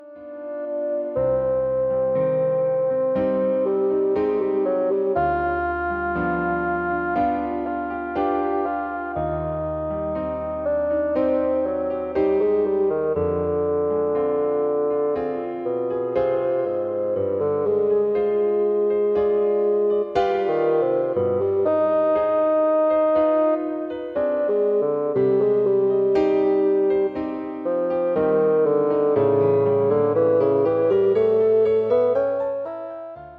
for bassoon and piano
A Sonato for Basoon and piano.
The audio clip is the second movement - Lento